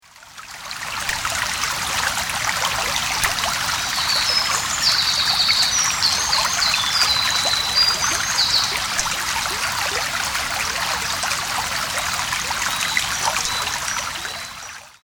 川のせせらぎ、鳥のさえずり、波の音、虫の声、
気持ちの良い自然音と、カリンバ、サヌカイトなどの楽器、
水琴窟の響きなどをミックス。
アカショウビン